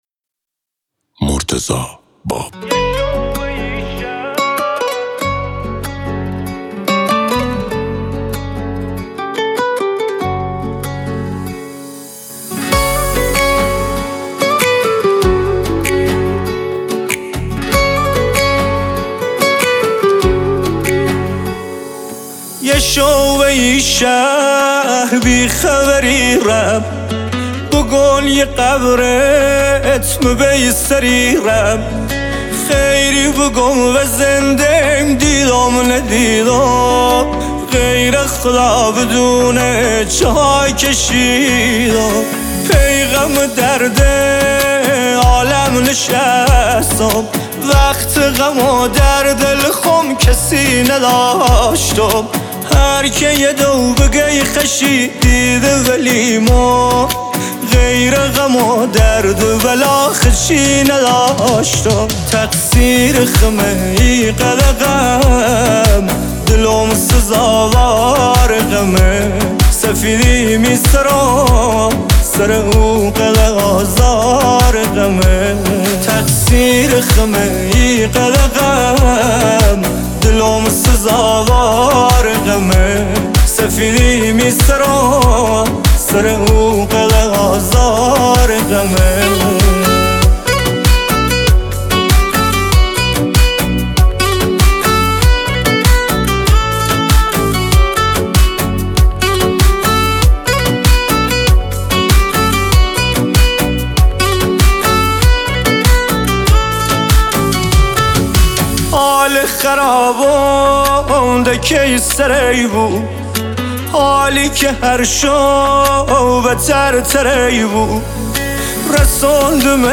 غمگین لری